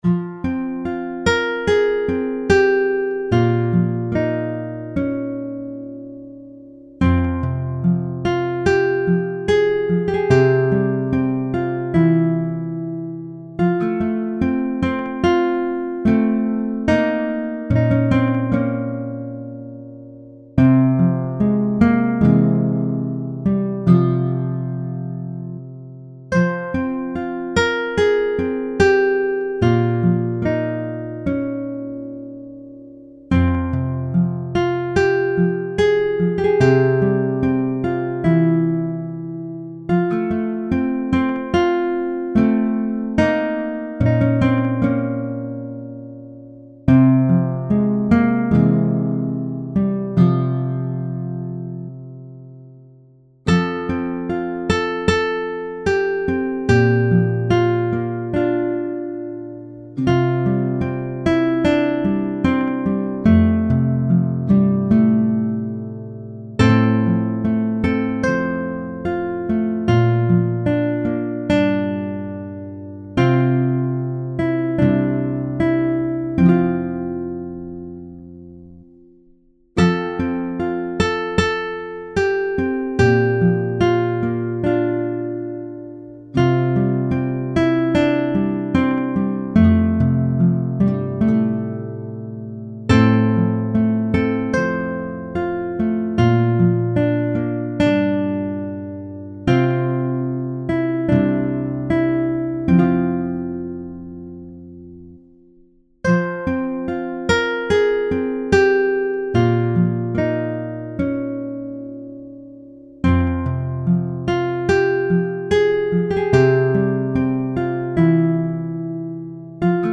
Largo [0-10] - - guitare - harpe - aerien - folk - melodieux
guitare - harpe - aerien - folk - melodieux